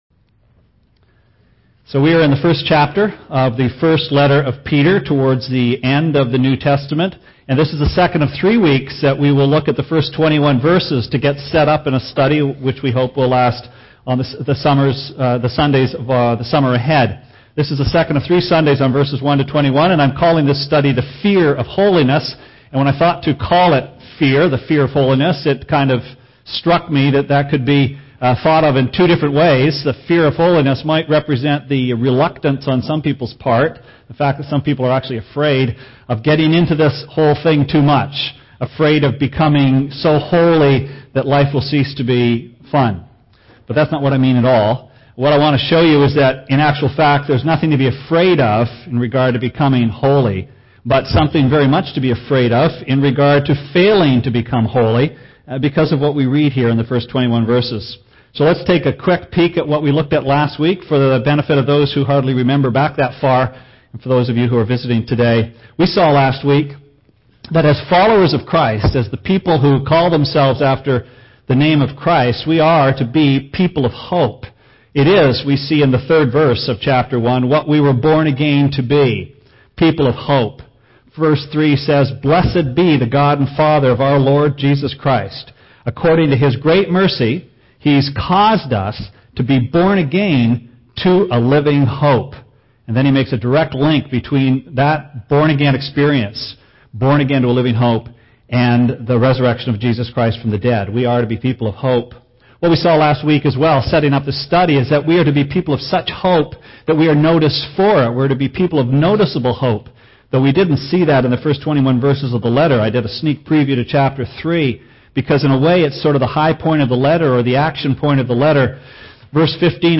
Sermon Archives - West London Alliance Church